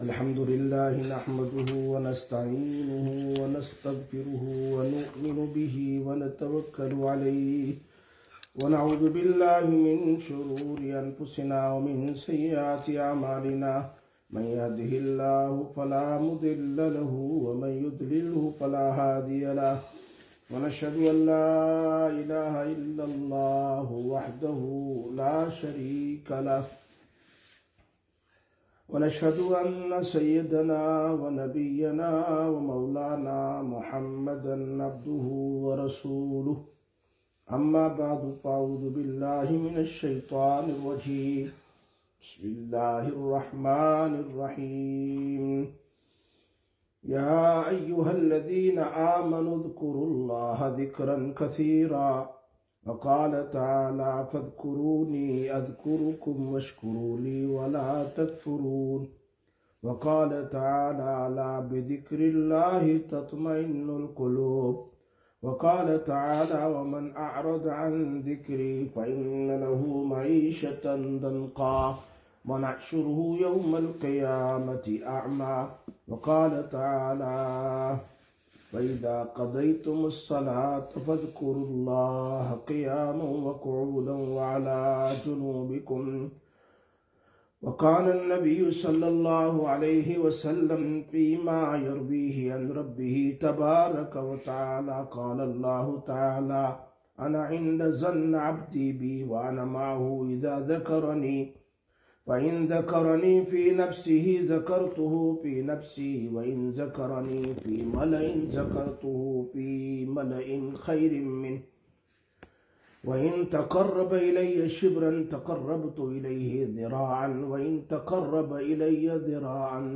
26/11/2025 Sisters Bayan, Masjid Quba